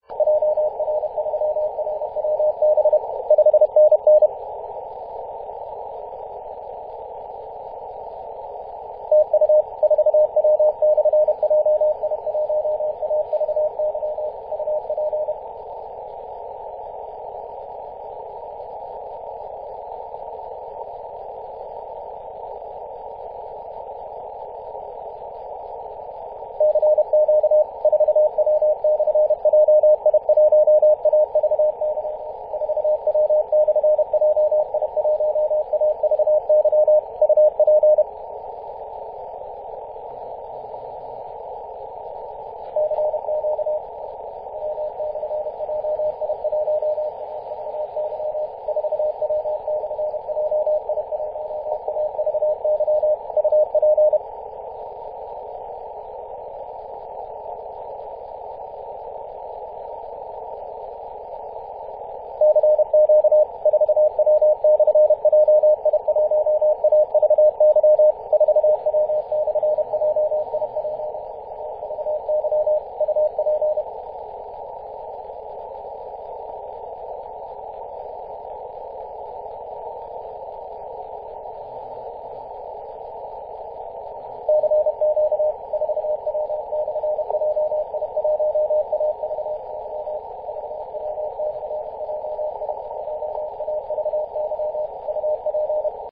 21.011MHz CW